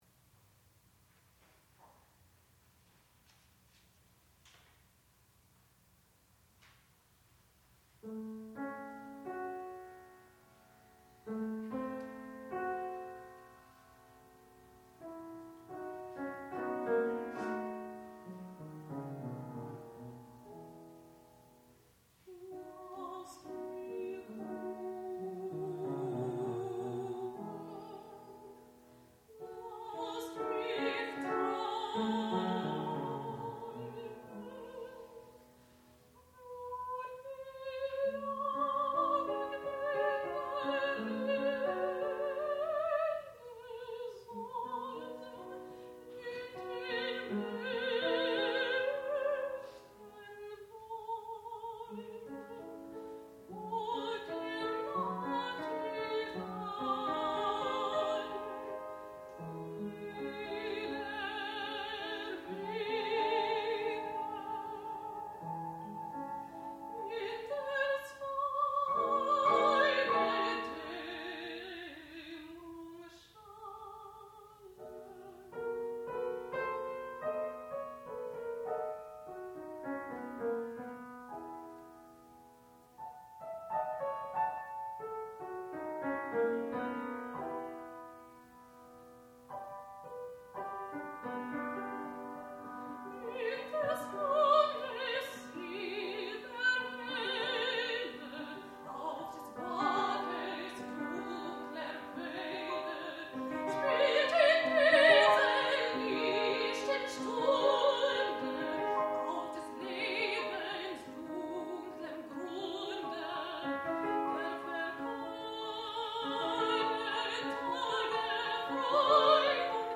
sound recording-musical
classical music
piano
mezzo-soprano
Junior Recital